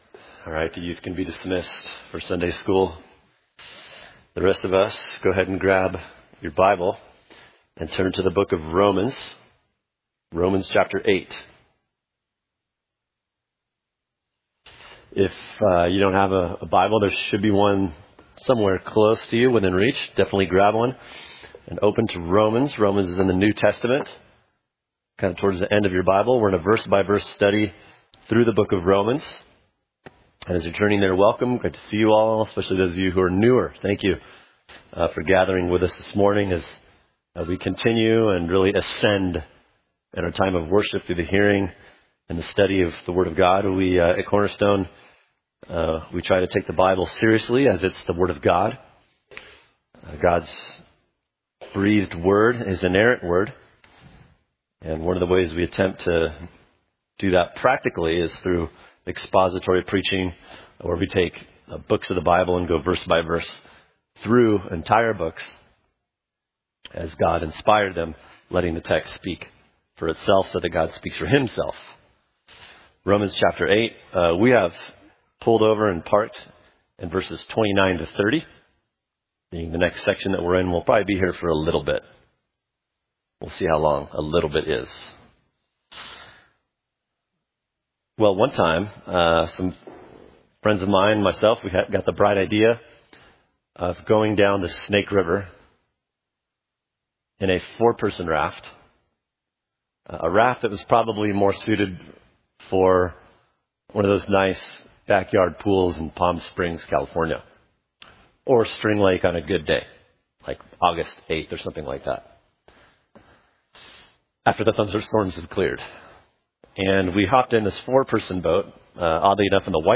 [sermon] The Love of God and the Golden Chain of Salvation: Predestination Romans 8:29 | Cornerstone Church - Jackson Hole